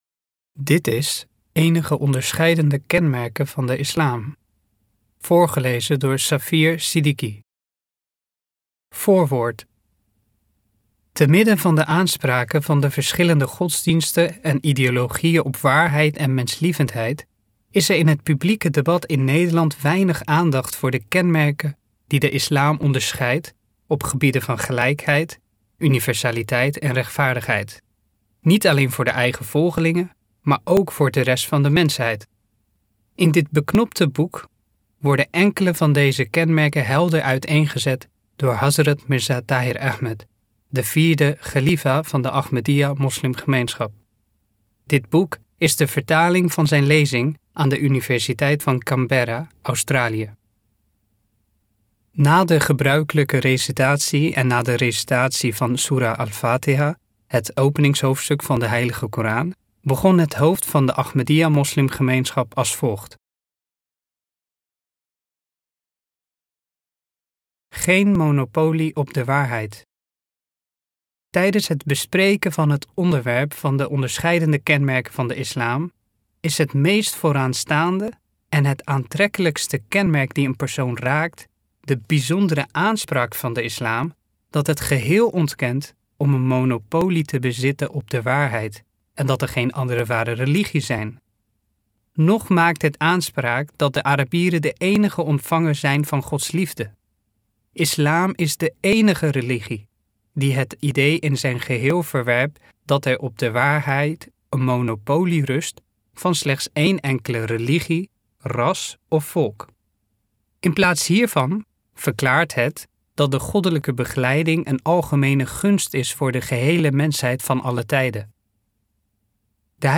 Downloads: Download PDF Download Audio Book Download Ebook